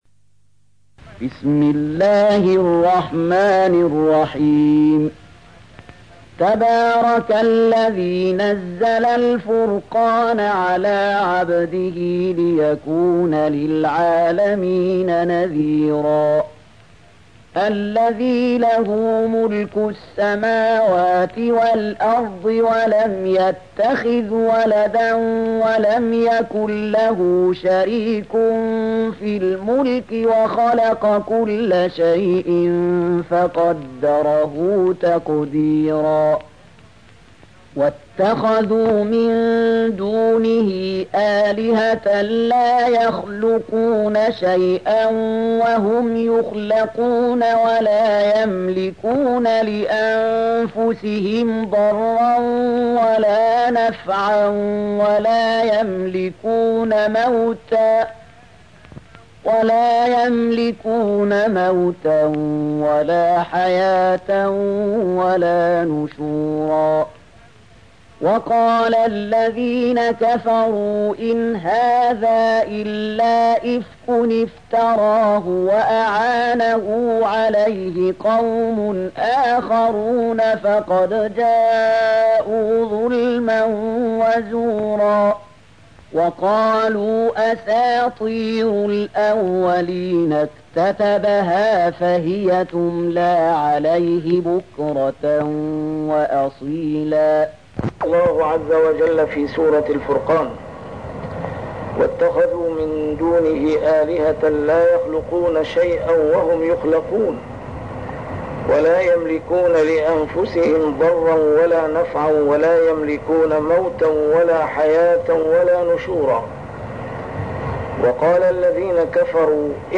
A MARTYR SCHOLAR: IMAM MUHAMMAD SAEED RAMADAN AL-BOUTI - الدروس العلمية - تفسير القرآن الكريم - تسجيل قديم - الدرس 202: الفرقان 03-05